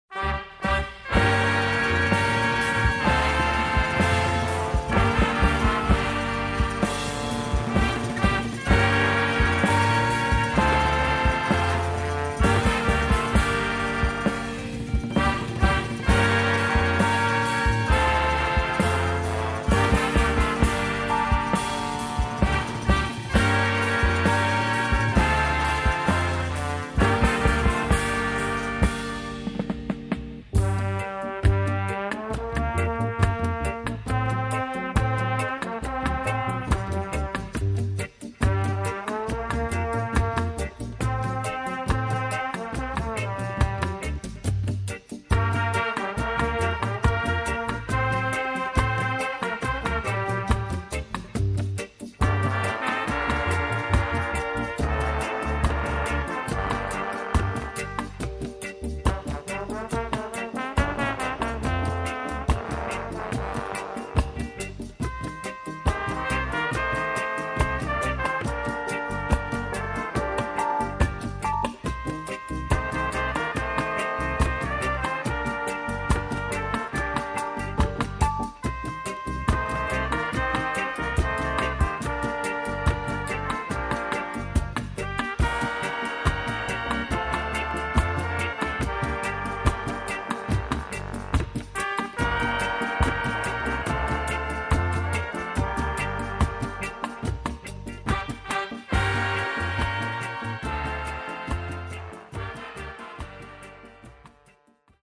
Afro、Funk、Jazz、Calypso、Mentなど様々な音楽を消化したオリジナリティ溢れる傑作。